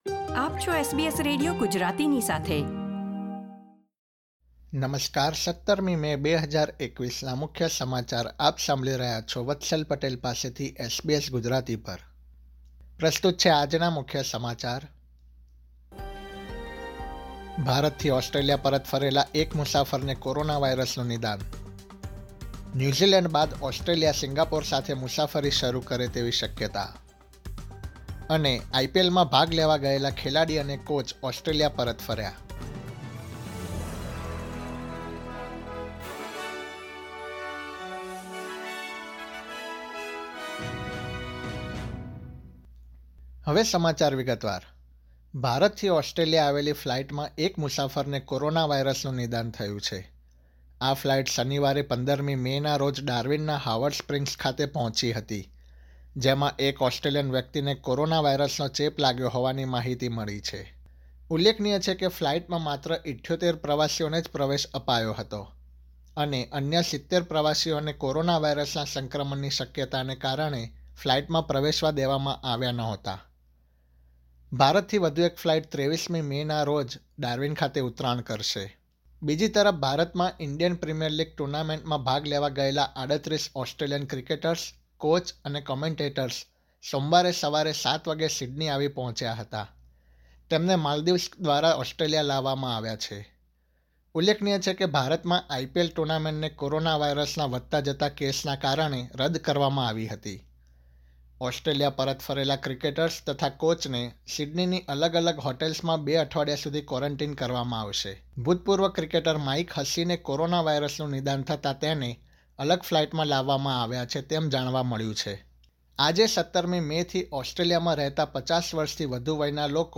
SBS Gujarati News Bulletin 17 May 2021